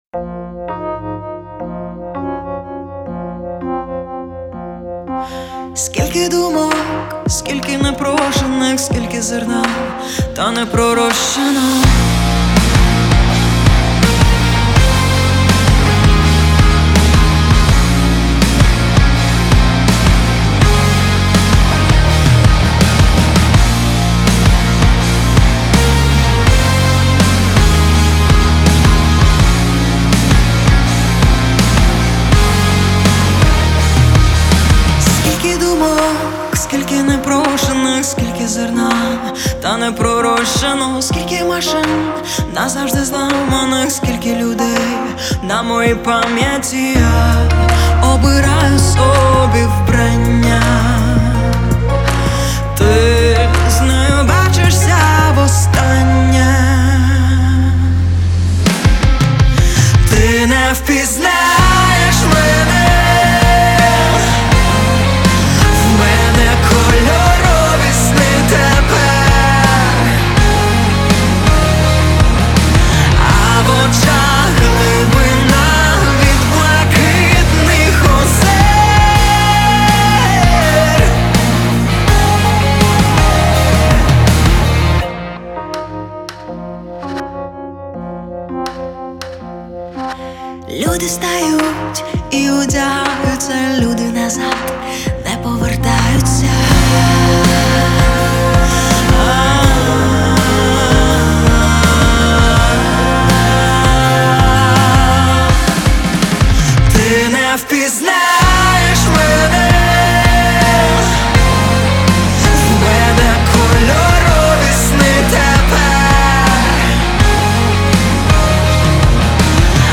• Жанр:Рок